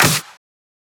edm-clap-03.wav